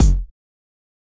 Kick (6).wav